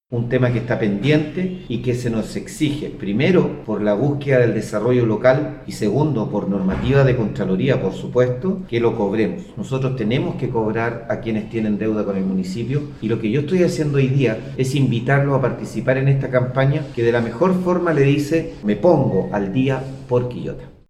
03-ALCALDE-Debemos-cobrarlo.mp3